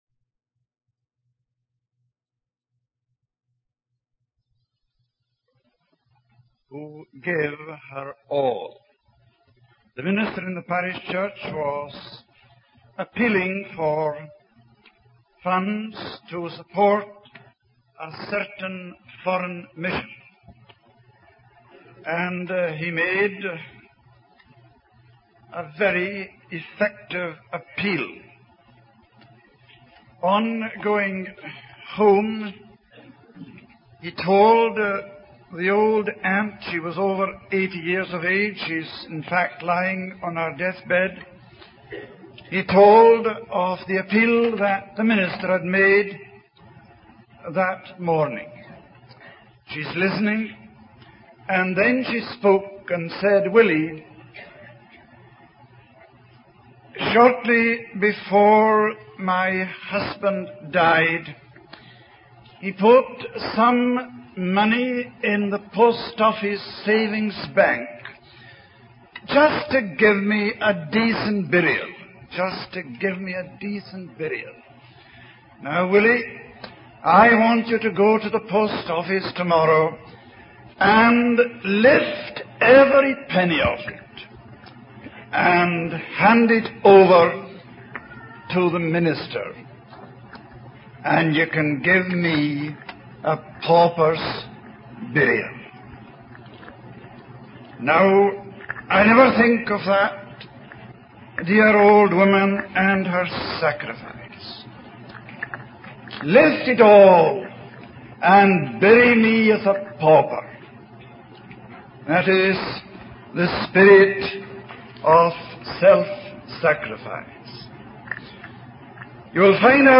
In this sermon, the speaker emphasizes the concept of God's second chance. He highlights that after death, there is no second chance, but in life, God gives us the opportunity to change and transform. The speaker refers to the story of Jacob and how God met him on his journey, symbolizing God's pursuit of us.